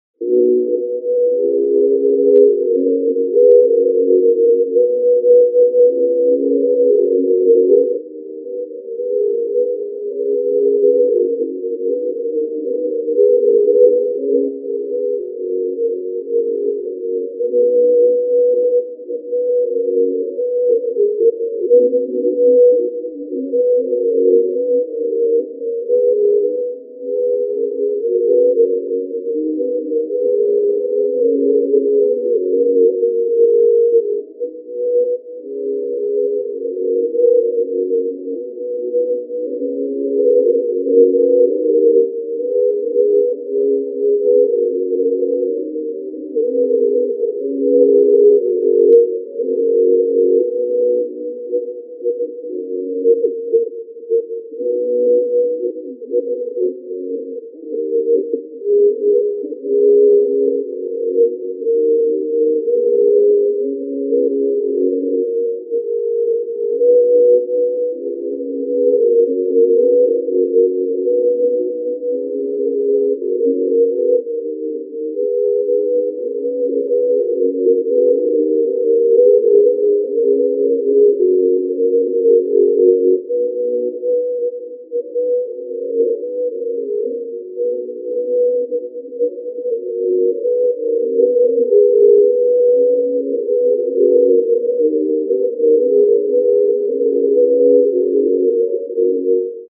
...В общем, приходилось когда-то слышать искомую мелодию по советскому радио, - в наигрыше: то ли на гармони, то ли на баяне.
Плюс: там многое - записано, видимо, с радиоприёмника на отдалённый микрофон, - вот фон да гул приглушённый, только и остались)...
Пусть уж запись и глуховата...
Только вот, поднятый сейчас "на свет" - баянный/гАрмонный наигрыш, ужасного качества!